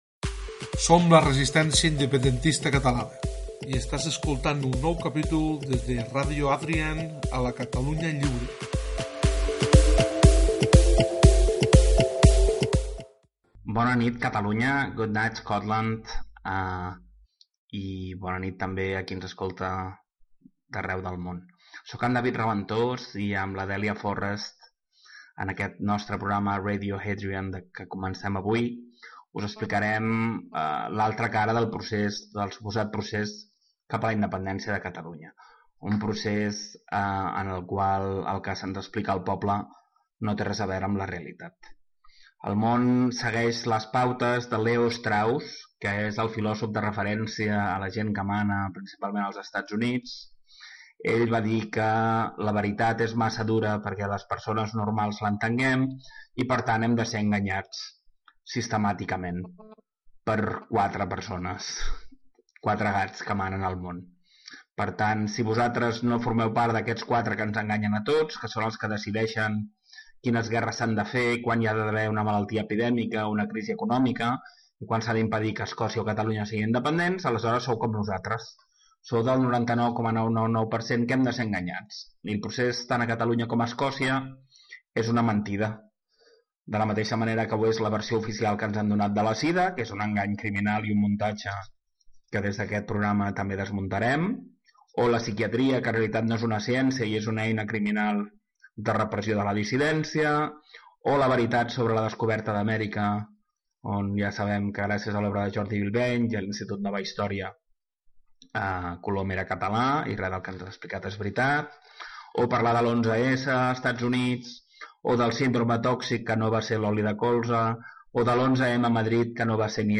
Careta del programa, presentació. Reflexió sobre l'ús de l'engany com a eina de manipulació política. La censura als mitjans de comunicació catalans en la informació del procés polític de la independència de Catalunya.
Informatiu